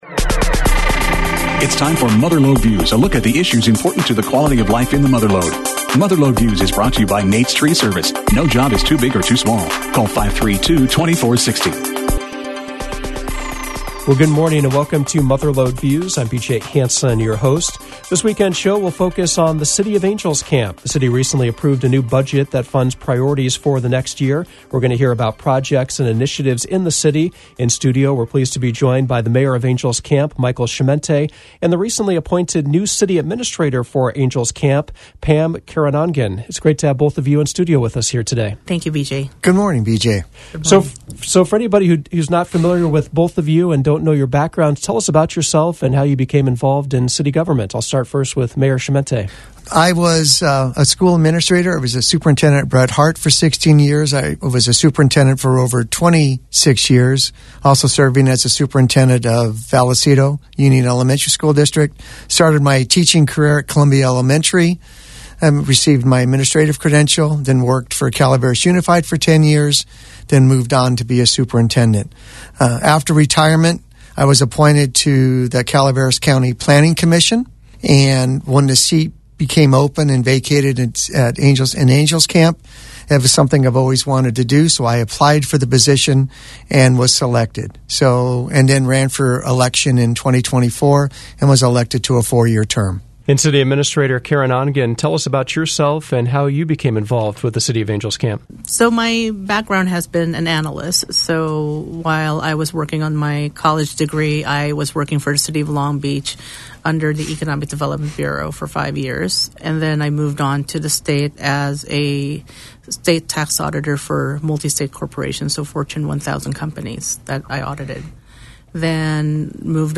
Mother Lode Views featured Angels Camp Mayor Michael Chimente and new City Administrator Pamela Caronongan. They spoke about several challenges and opportunities facing the city. Some of the topics included the new budget, recent park improvements, water initiatives, Caltrans projects, the potential for annexation, fire department improvements, and this week’s selection of a new council member.